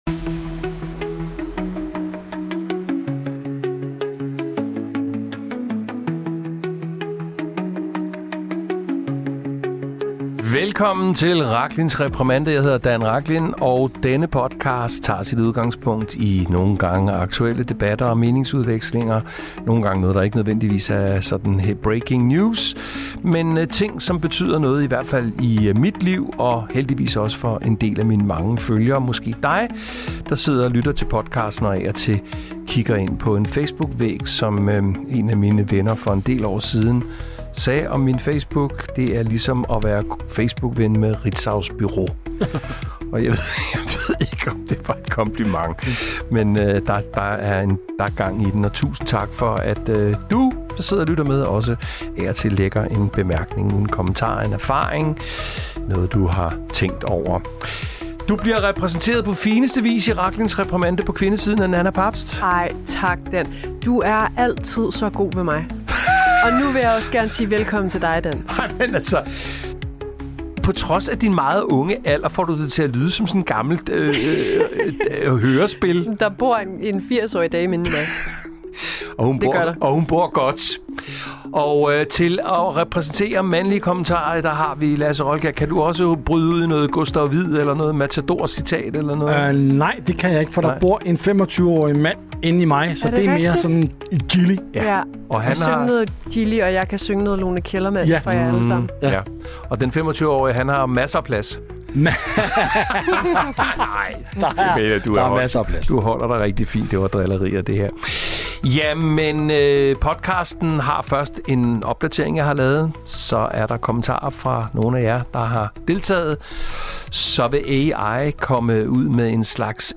Medværter og stemmer til kommentarsporet